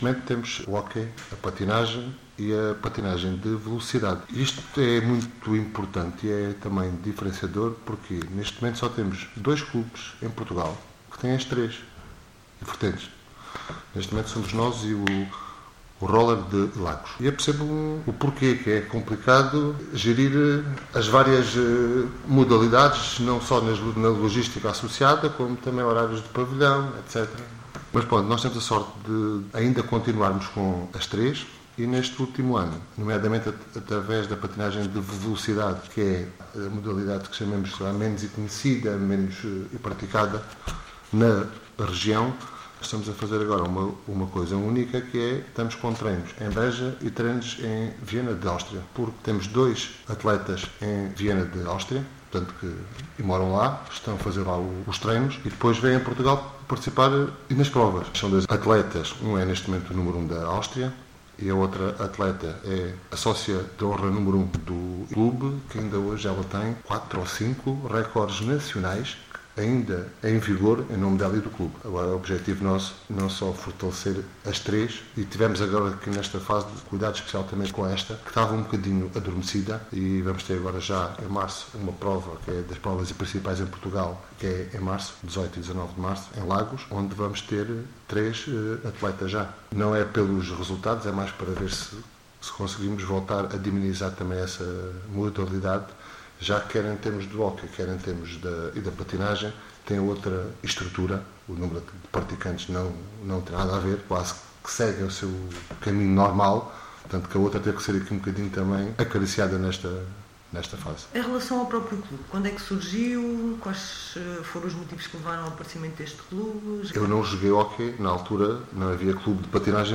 A entrevista da semana